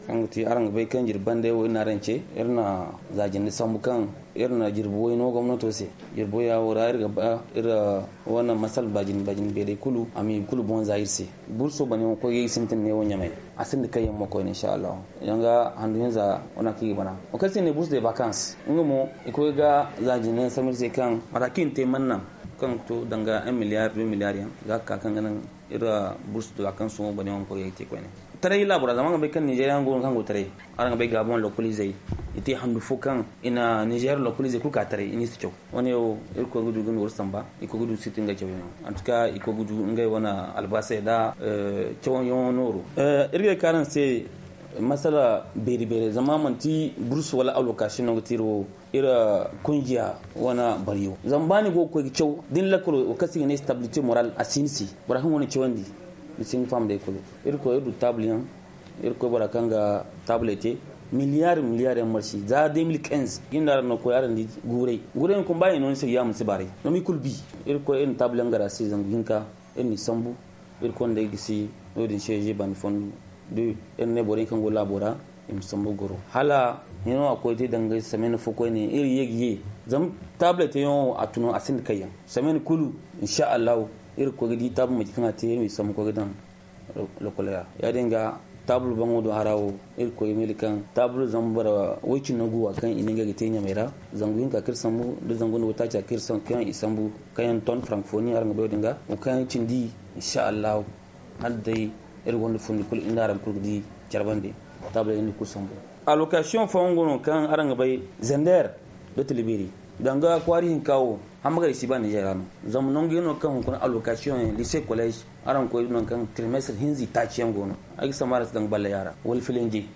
Le Comité Directeur de l’Union des Scolaires Nigériens (USN) a animé un point de presse ce matin à Niamey pour faire le bilan de l’ultimatum de dix Jours lancé au Gouvernement le 17 Novembre dernier afin de régler les problèmes de l’Ecole Nigérienne.